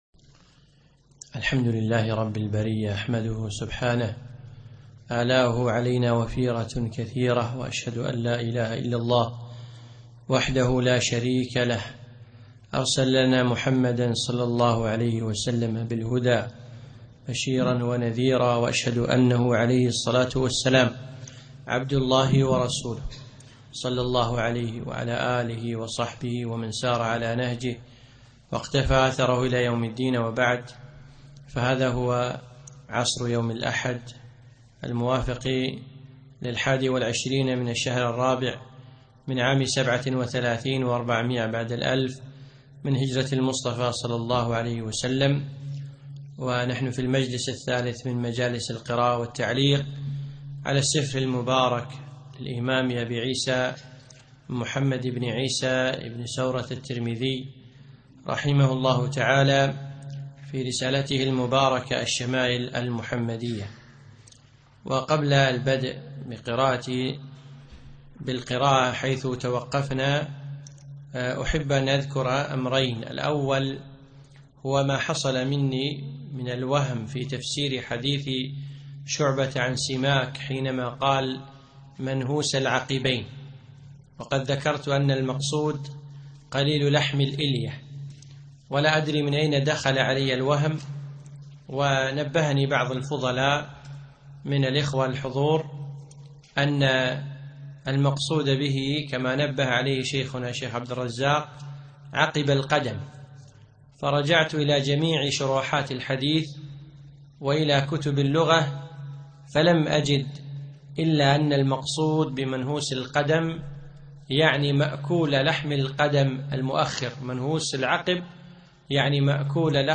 يوم الأحد 22 ربيع الأخر 1437هـ الموافق 1 2 2016م في مسجد عائشة المحري المسايل